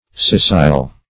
Scissile \Scis"sile\, a. [L. scissilis, fr. scindere, scissum,